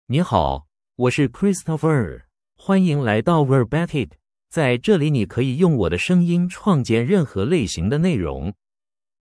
Christopher — Male Chinese AI voice
Christopher is a male AI voice for Chinese (Mandarin, Traditional).
Voice sample
Listen to Christopher's male Chinese voice.
Christopher delivers clear pronunciation with authentic Mandarin, Traditional Chinese intonation, making your content sound professionally produced.